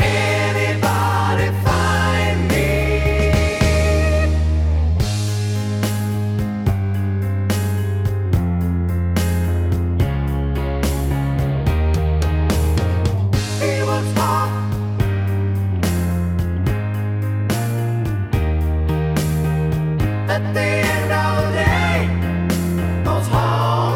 With Claps Rock 5:00 Buy £1.50